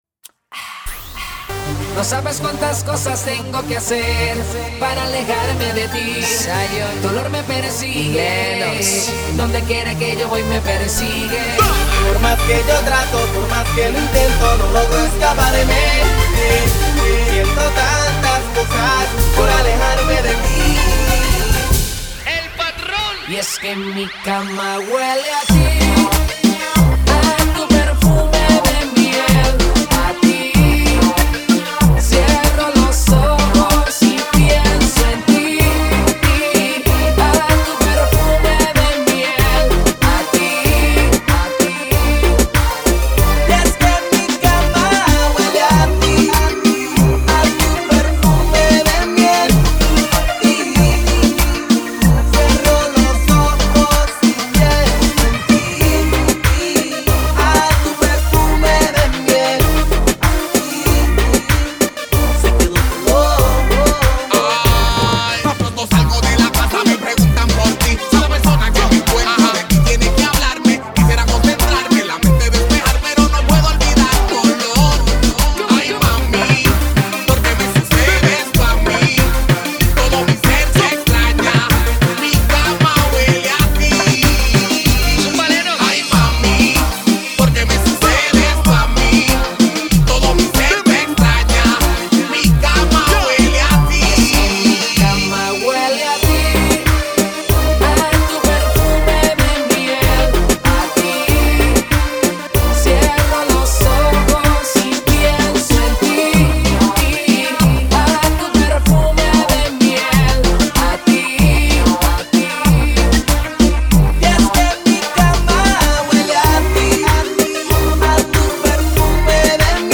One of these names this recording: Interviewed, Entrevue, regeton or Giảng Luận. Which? regeton